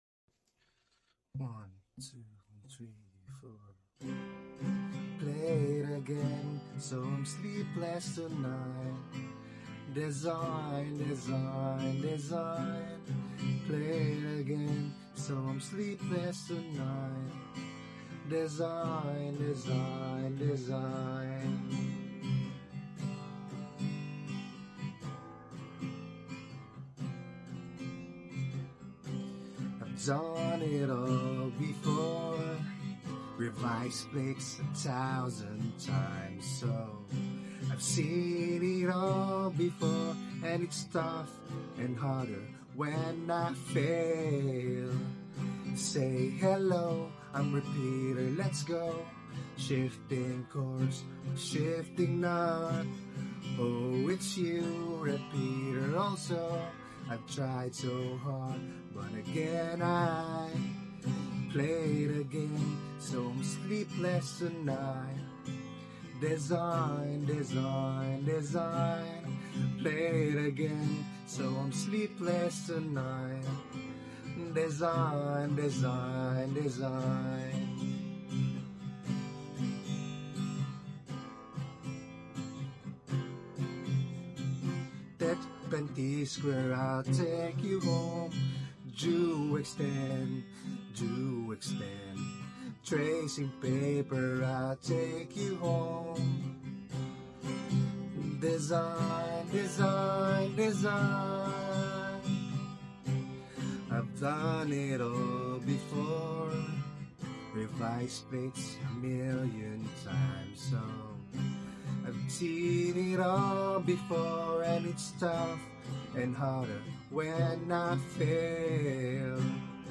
ACOUSTIC VER.